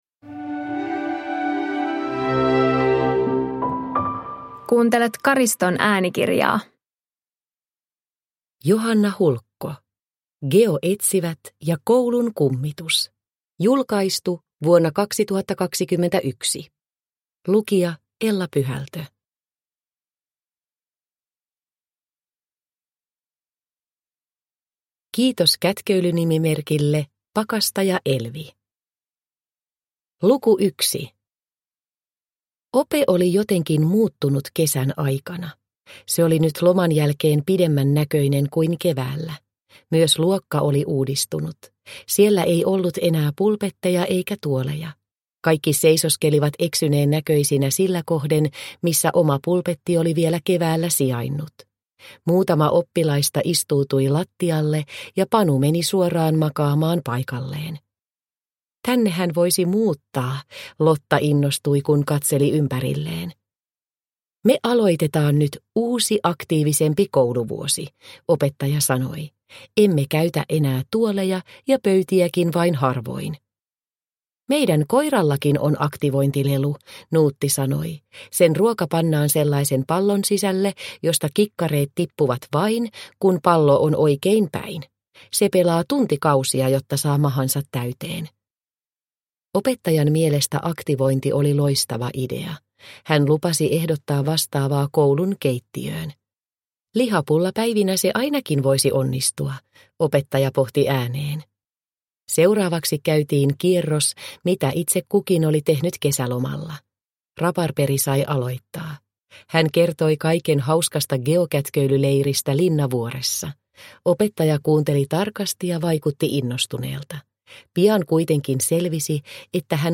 Geoetsivät ja koulun kummitus – Ljudbok – Laddas ner